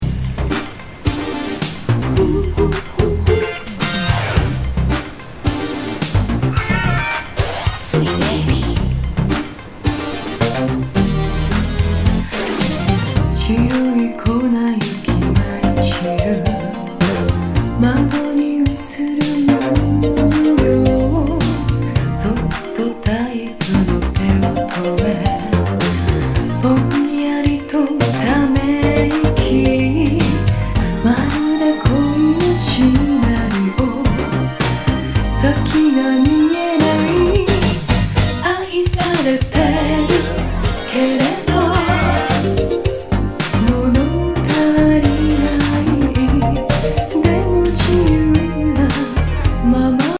極上シンセ・メロウ・グルーブ